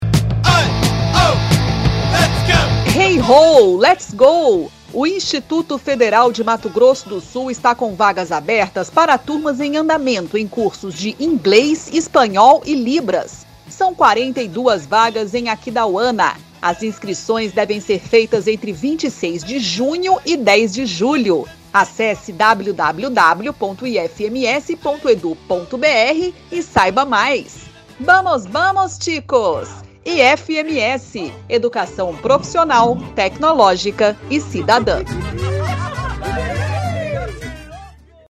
Spot - Cursos de Idiomas em Aquidauana
Áudio enviado às rádios para divulgação institucional do IFMS